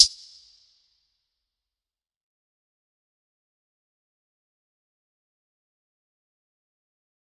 DMV3_Hi Hat 5.wav